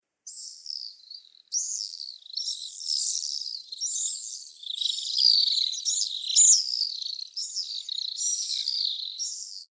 アマツバメ｜日本の鳥百科｜サントリーの愛鳥活動
「日本の鳥百科」アマツバメの紹介です（鳴き声あり）。